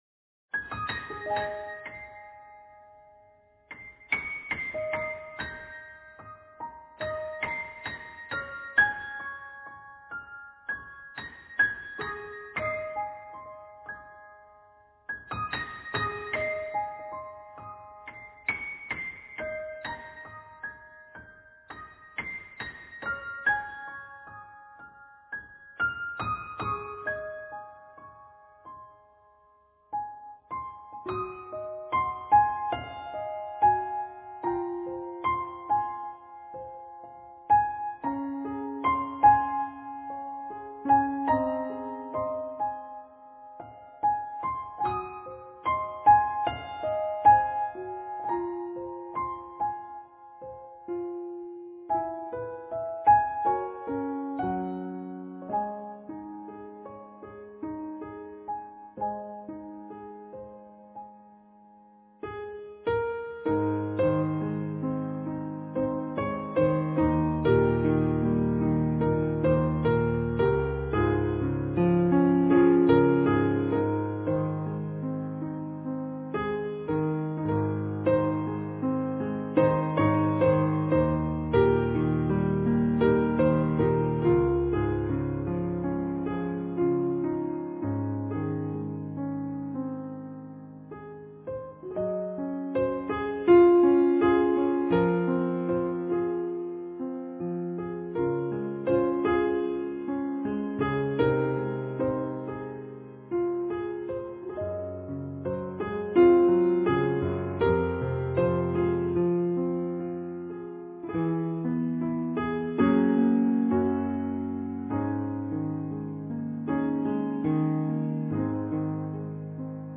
青空--推荐 冥想 青空--推荐 点我： 标签: 佛音 冥想 佛教音乐 返回列表 上一篇： 慈心-纯音乐--佛光山梵呗团 下一篇： 水波粼粼（纯音乐）--陈佩廷 相关文章 静空灿烂（纯音乐）--马常胜 静空灿烂（纯音乐）--马常胜...